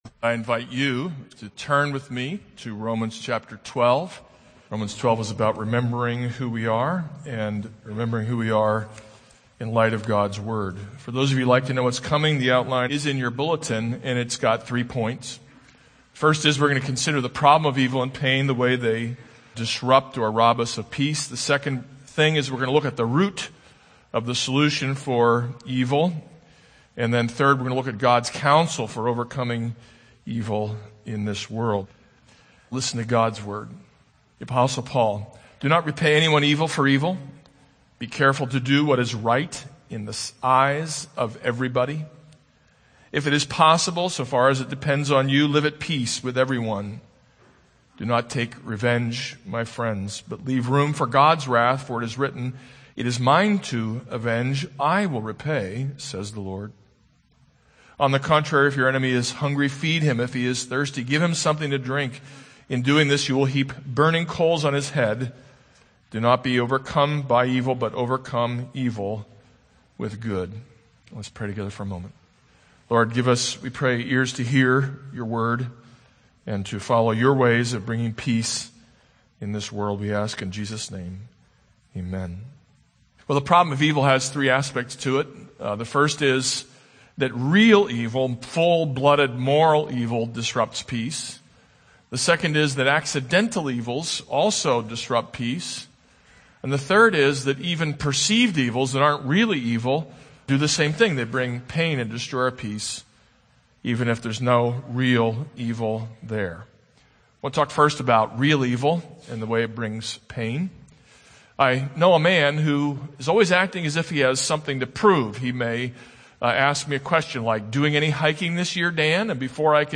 This is a sermon on Romans 12:17-21.